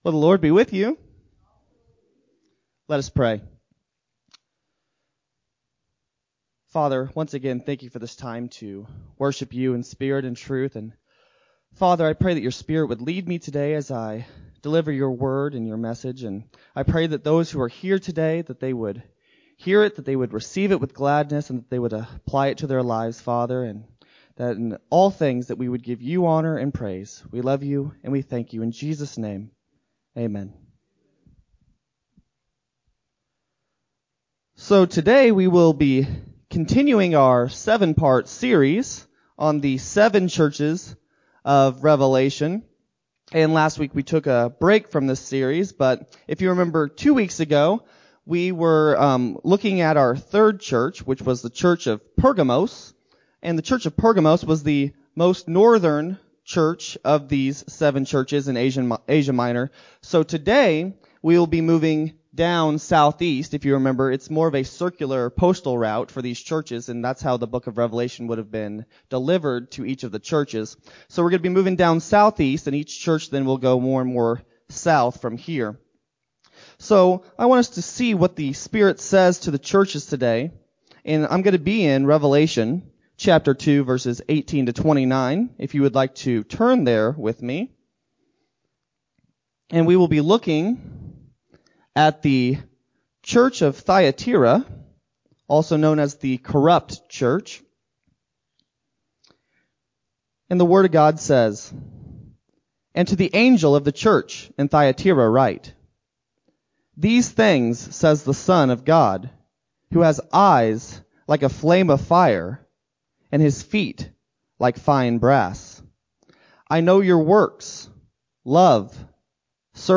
6-28-20-Sermon-BU-CD.mp3